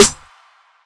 Trp_Clap_Snr.wav